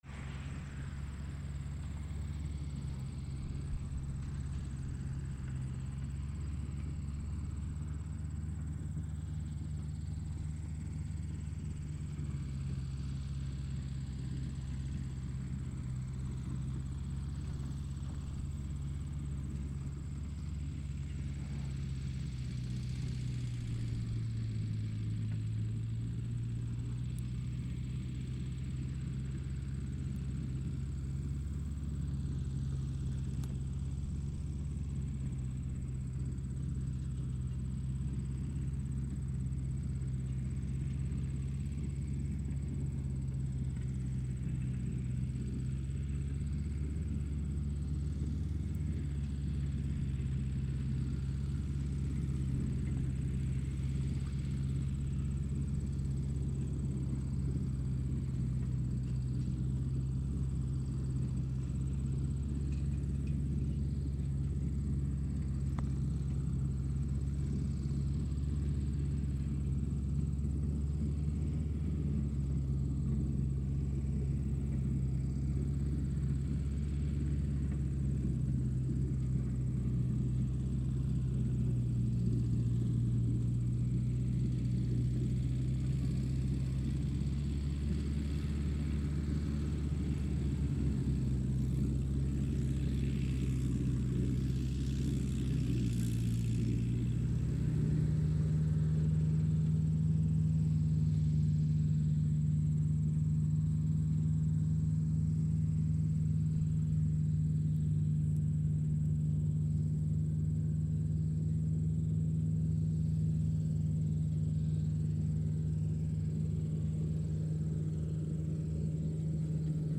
Шум трактора и фоновые звуки